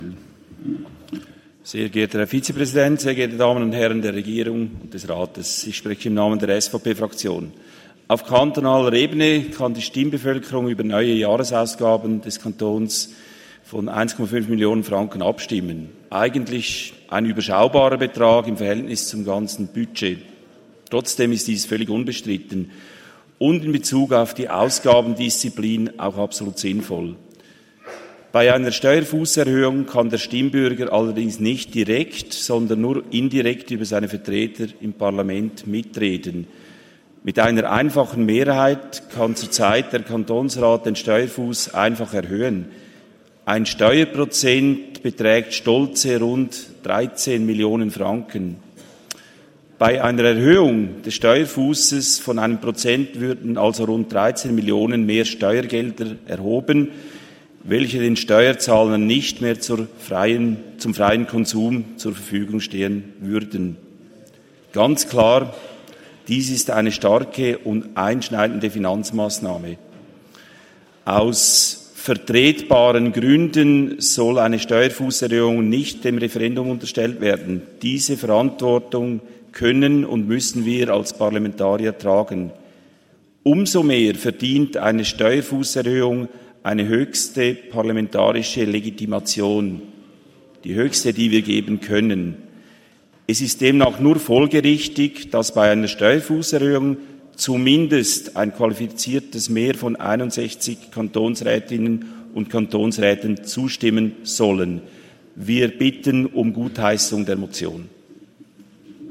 16.9.2024Wortmeldung
Session des Kantonsrates vom 16. bis 18. September 2024, Herbstsession